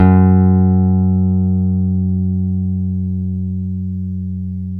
Index of /90_sSampleCDs/Roland LCDP02 Guitar and Bass/BS _Rock Bass/BS _Stretch Bass